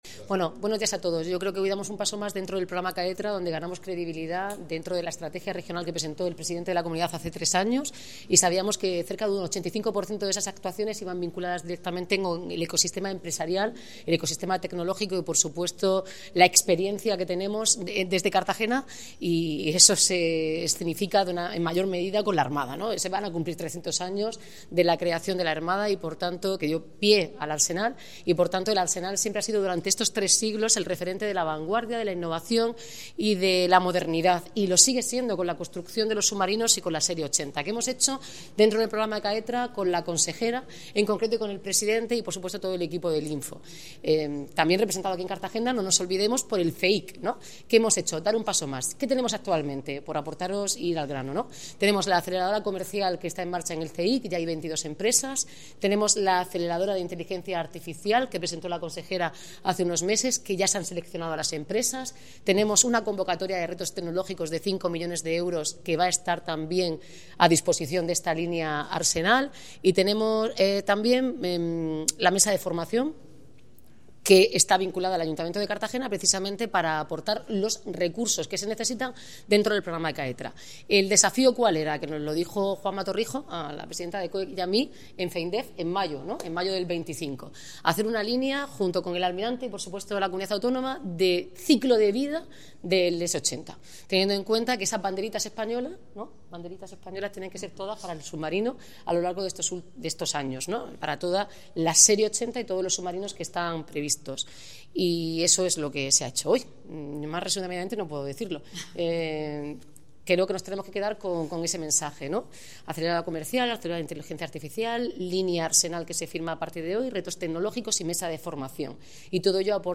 Declaraciones de la alcaldesa Noelia Arroyo, el almirante Alejandro Cuerda y la consejera Mar�a Isabel L�pez.